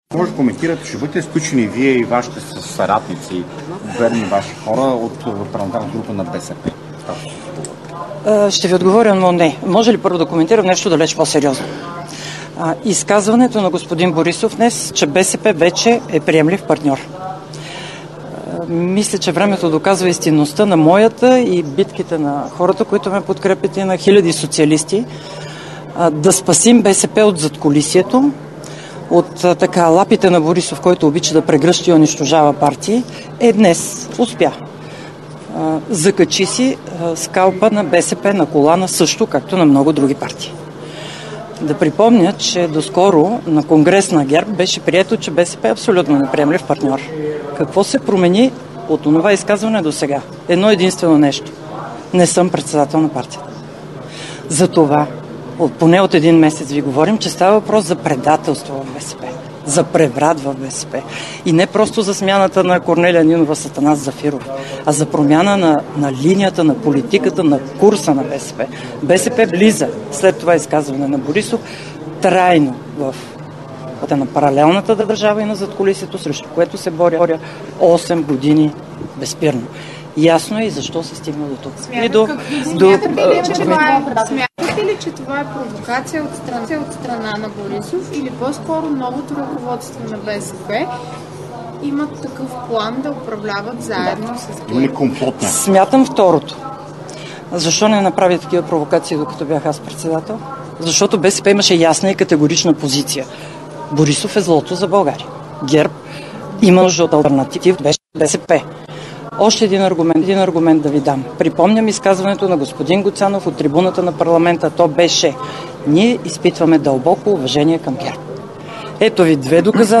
11.05 - Заседание на Министерски съвет.
директно от мястото на събитието (Министерски съвет)
Директно от мястото на събитието